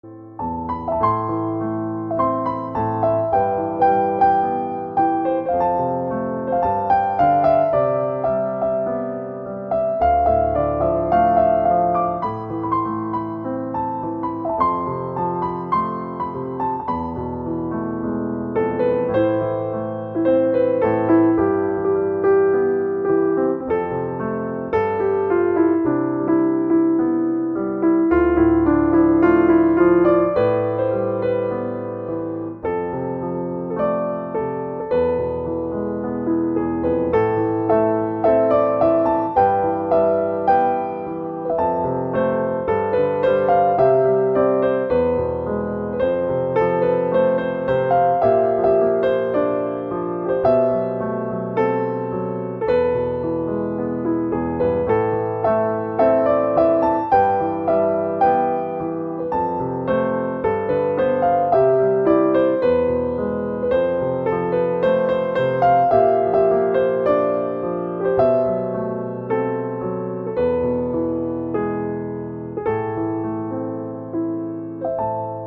nhẹ nhàng, sâu lắng